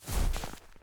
tbd-station-14/Resources/Audio/Effects/Footsteps/snowstep3.ogg at 0bbe335a3aec216e55e901b9d043de8b0d0c4db1
snowstep3.ogg